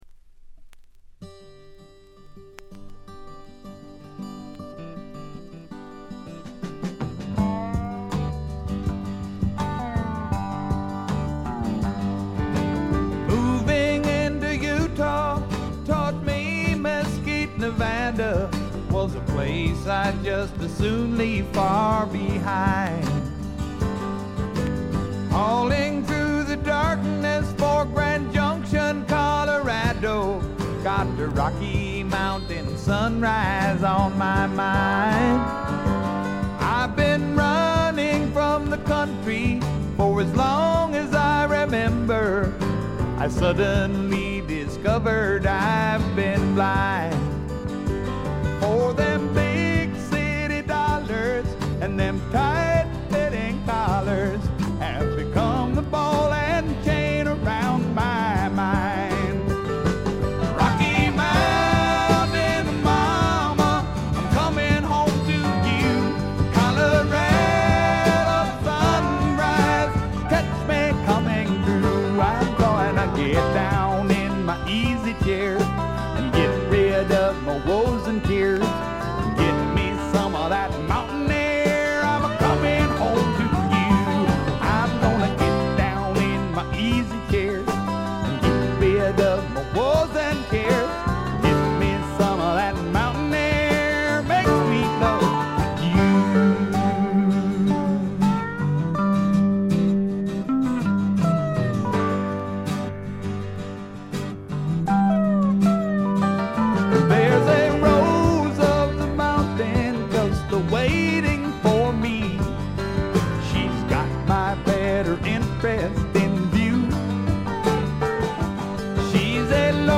ごくわずかなノイズ感のみ。
スワンプ系シンガーソングライター作品の基本定番。
試聴曲は現品からの取り込み音源です。
Vocals, Acoustic Guitar